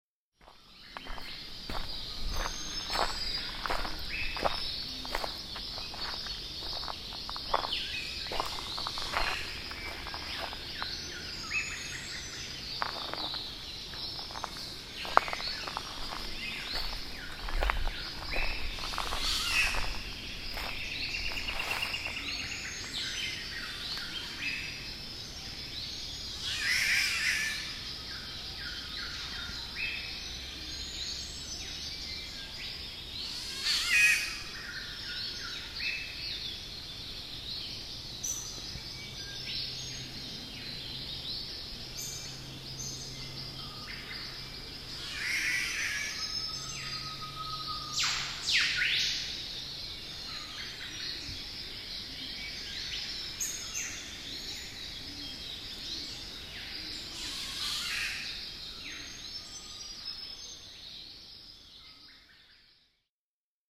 Spaziergang durch den Regenwald
Beim Spaziergang durch den Regenwald in Queensland, Australien lausche ich einem Catbird
regenwald_mit_catbird.mp3